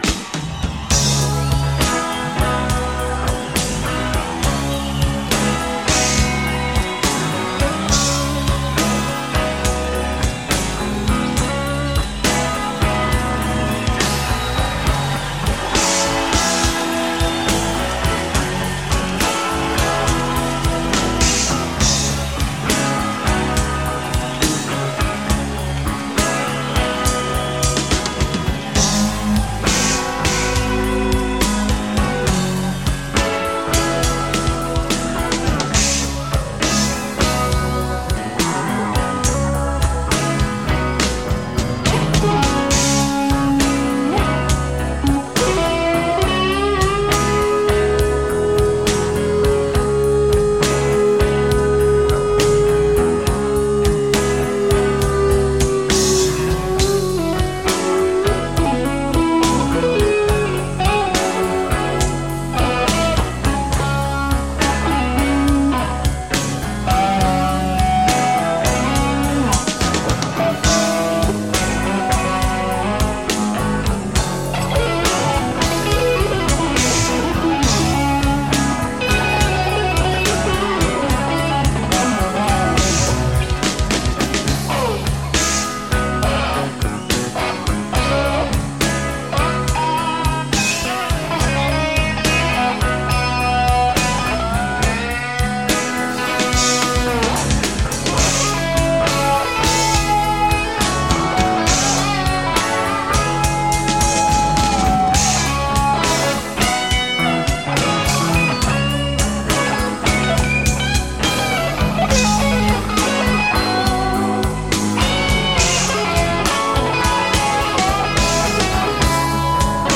Here he plays it straight on an instrumental blues solo.